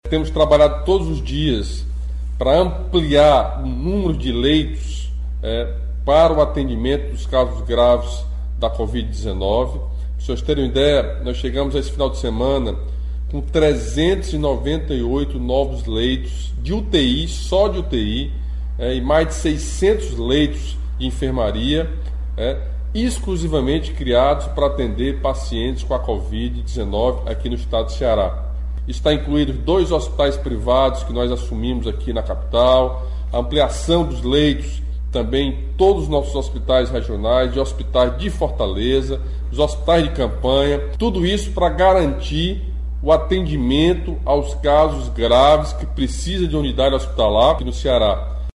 Em pronunciamento transmitido na noite deste sábado pelas redes sociais, o governador Camilo Santana ressaltou que o Governo do Ceará tem trabalhado fortemente nesse sentido e anunciou que, na rede do estado, já são 398 os leitos de UTI e 600 de enfermarias criados exclusivamente para receber pacientes com a Covid-19.